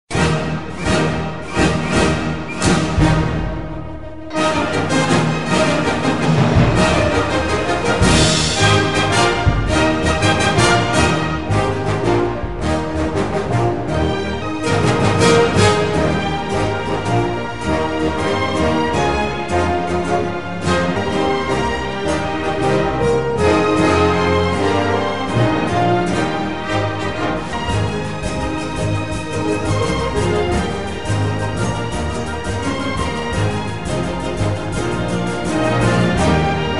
激情的背景音乐